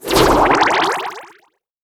sfx_skill 12.wav